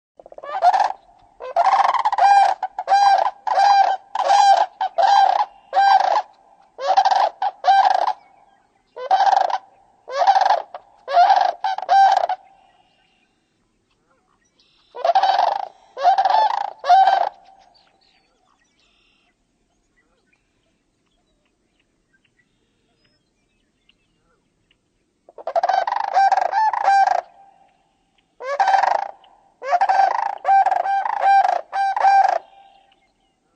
Sandhill Crane
Bird Sound
A deep, rolling trumpet and rattling.
SandhillCrane.mp3